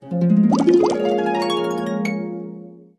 Музыка для раскрытия лотоса